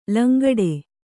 ♪ langaḍe